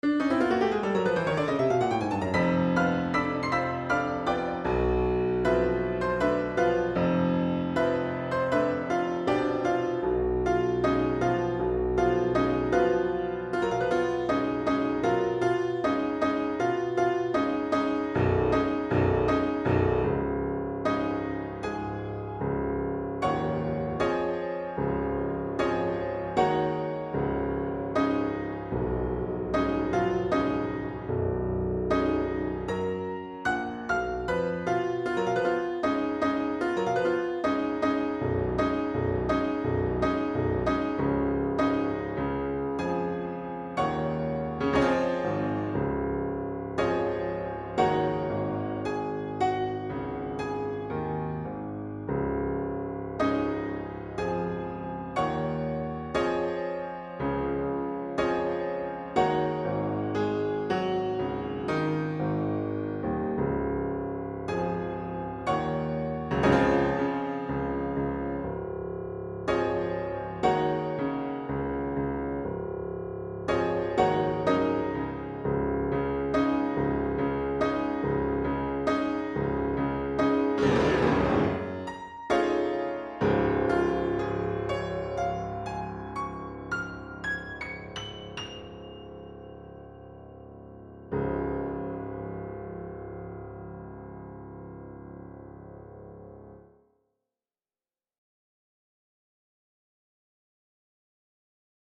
Selected Works for Piano